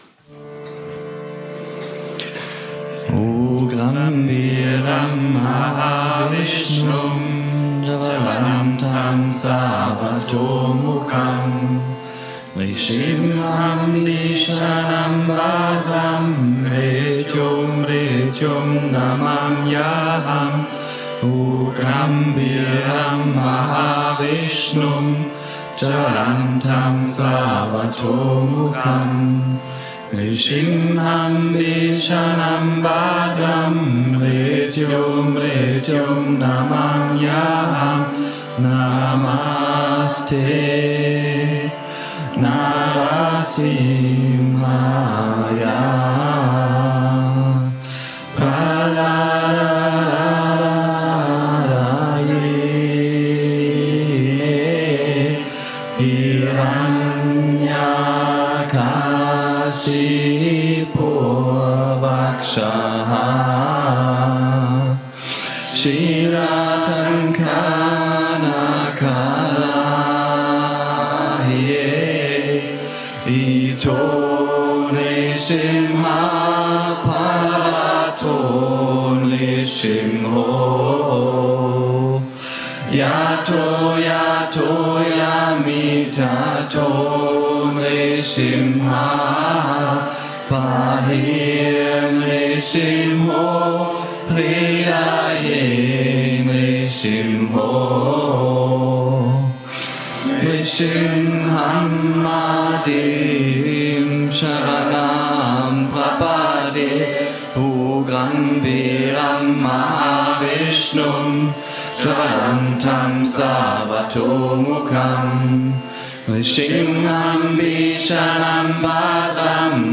Und dieser Kirtan besteht aus drei Strophen und
Gesungen wird dieser Kirtan in
dieser Aufnahme von neuen Yogalehrerinnen und Yogalehrer aus